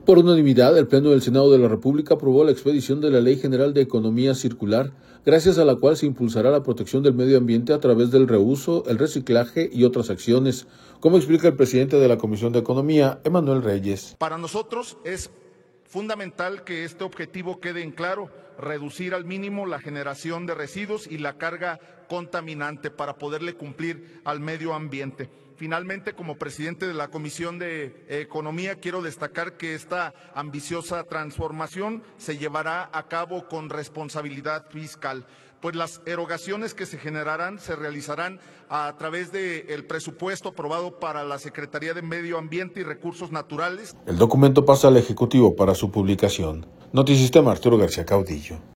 Por unanimidad, el Pleno del Senado de la República aprobó la expedición de la Ley General de Economía Circular, gracias a la cual se impulsará la protección del medio ambiente a través del reuso, el reciclaje y otras acciones, como explica el presidente de la Comisión de Economía, Emmanuel Reyes.